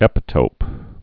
(ĕpĭ-tōp)